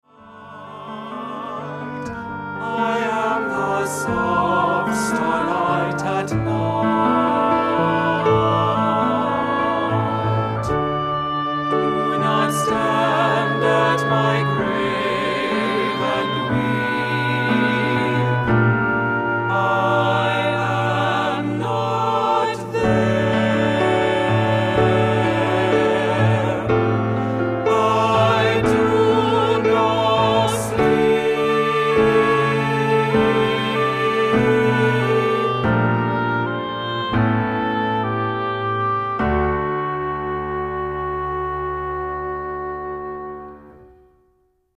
Voicing: Solo